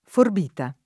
forbita